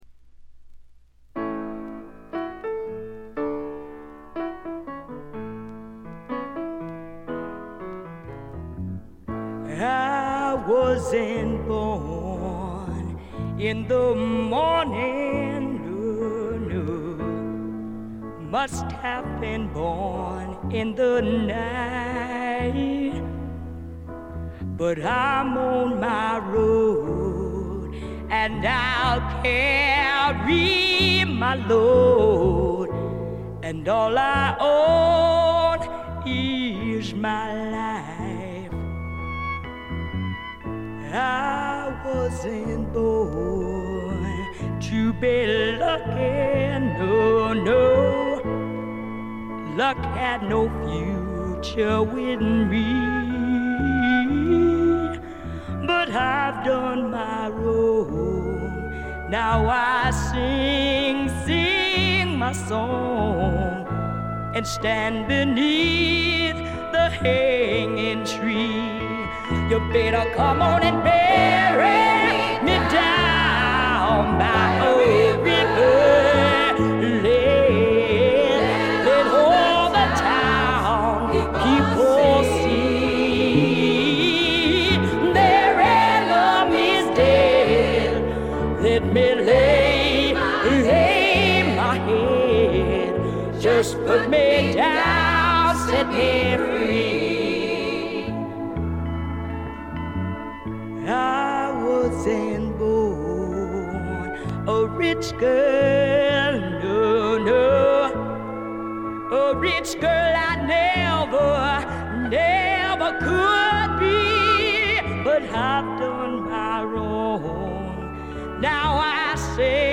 ほとんどノイズ感無し。
超重量級スワンプ名作。
試聴曲は現品からの取り込み音源です。